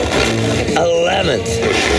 120BPMRAD2-L.wav